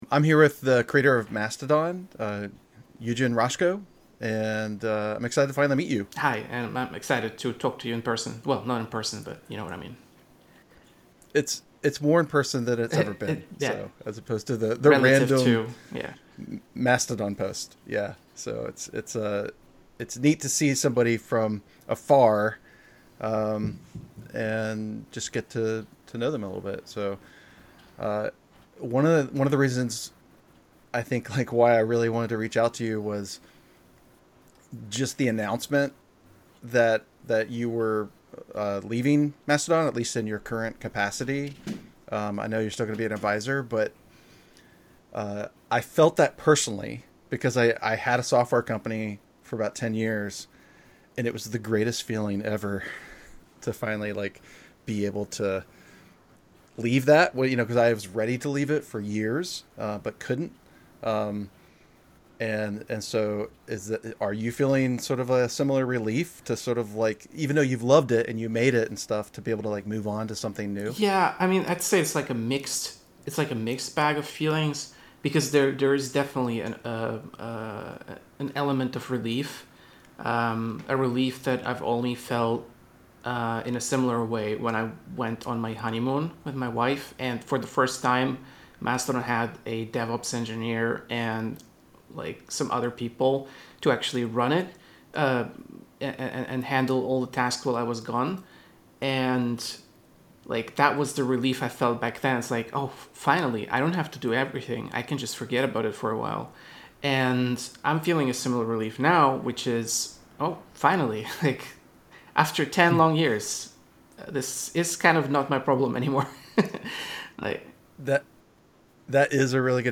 Eugen Rochko interview highlights